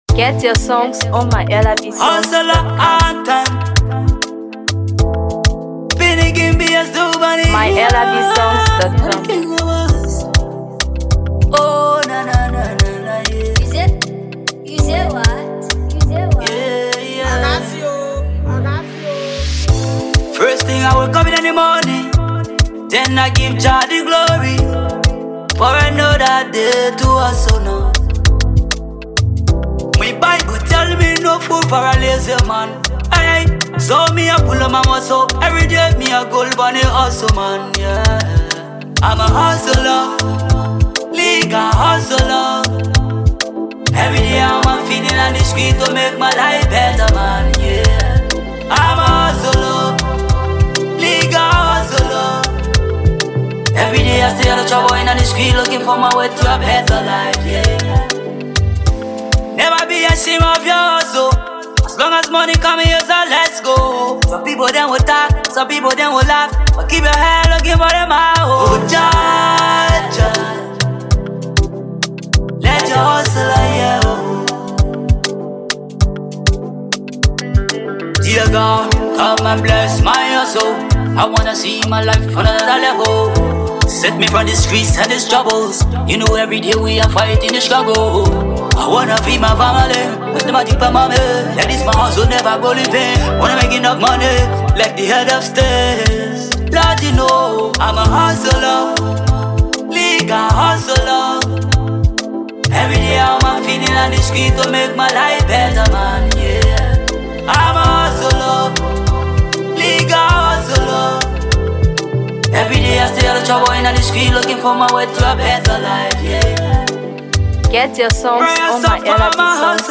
DancehallMusic